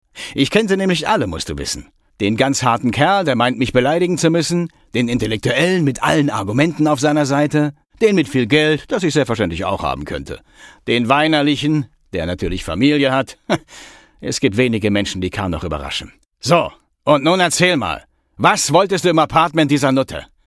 Goin' Downtown Sample 5 Datei herunterladen weitere Infos zum Spiel in unserer Spieleliste Beschreibung: Gangster Khan nimmt Jake in einer Szene gefangen. Ihr hört die Stimme von Antonio Banderas.